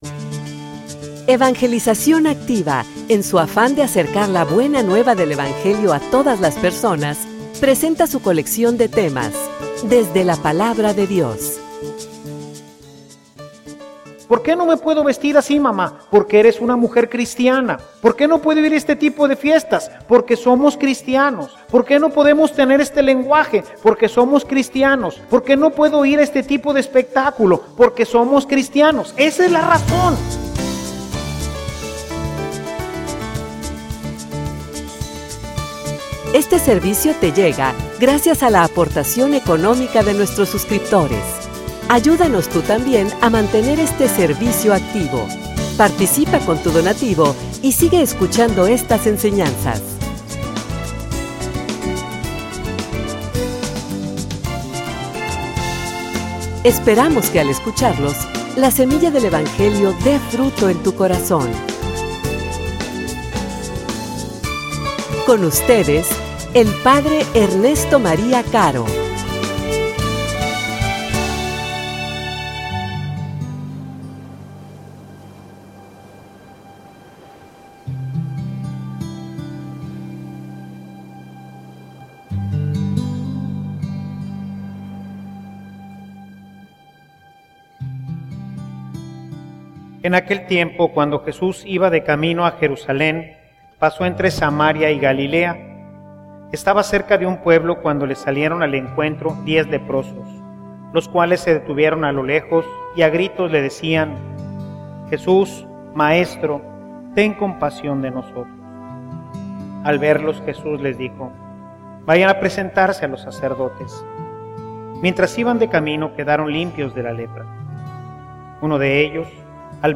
homilia_La_lepra_de_la_mundanidad.mp3